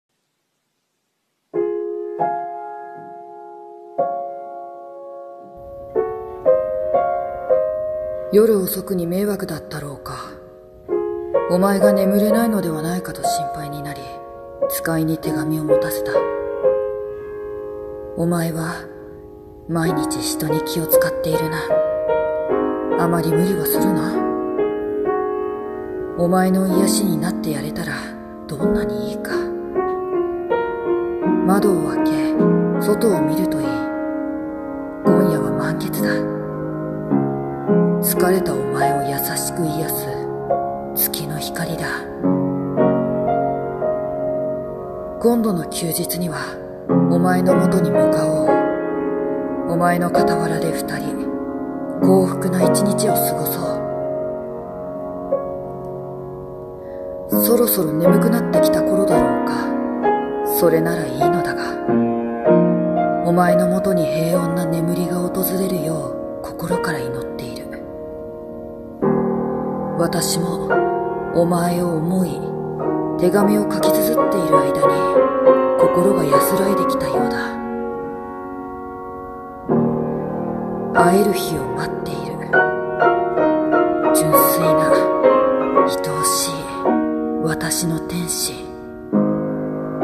声劇 王子からの手紙